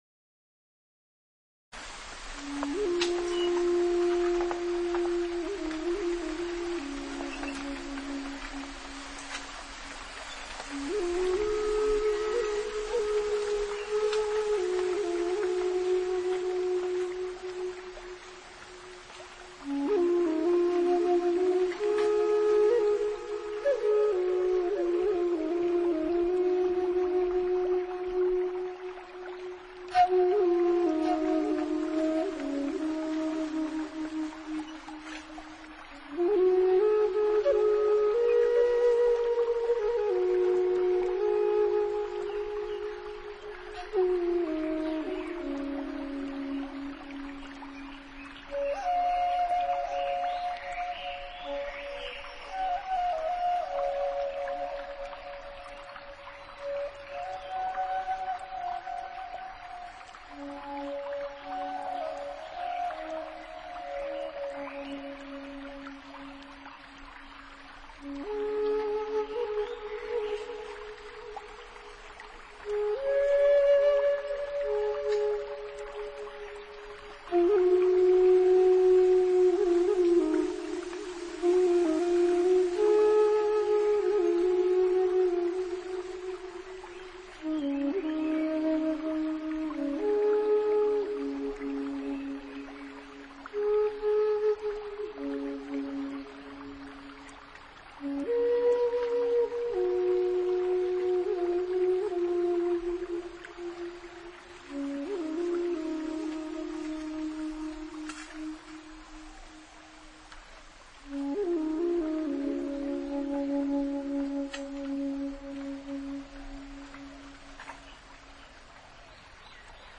【纯音乐】
水声加入此处，却有点金之笔。
音乐音响比较轻，适合在独处，或者宁静的氛